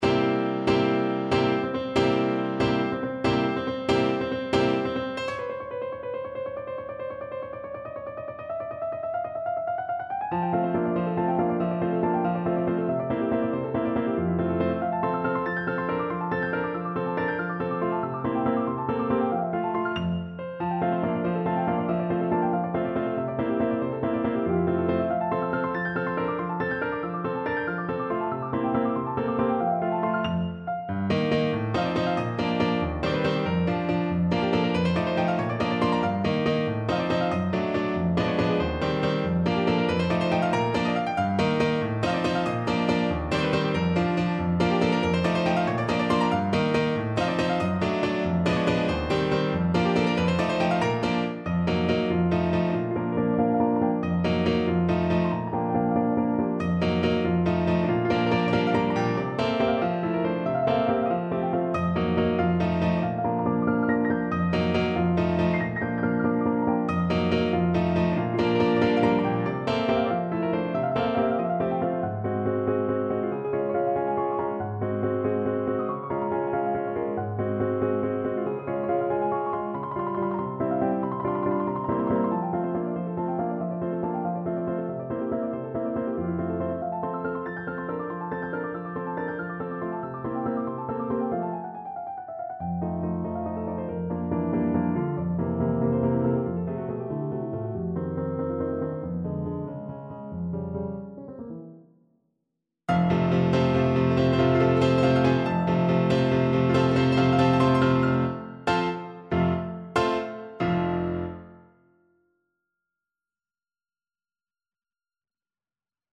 No parts available for this pieces as it is for solo piano.
Vivace =280 (View more music marked Vivace)
3/4 (View more 3/4 Music)
Piano  (View more Advanced Piano Music)
Classical (View more Classical Piano Music)